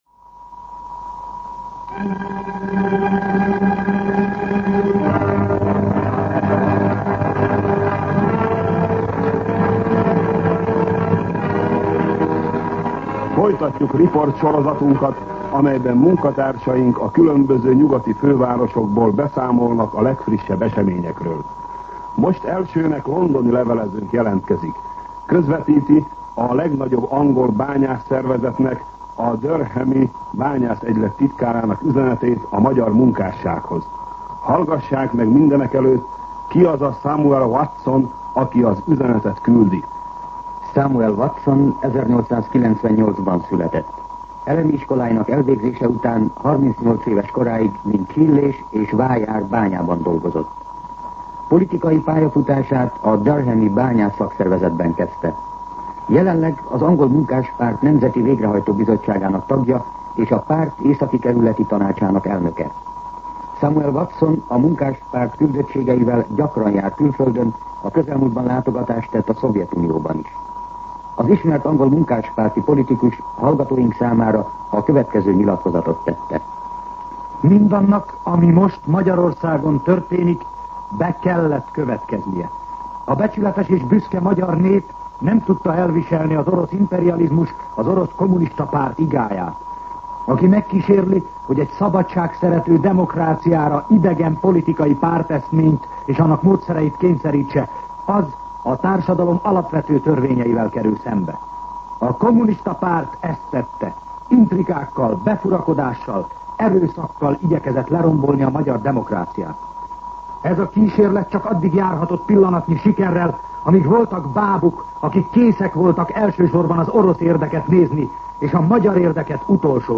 Rendkívüli riportsorozat a magyarországi események visszhangjáról - a nyugati fővárosokban működő tudósítóink jelentései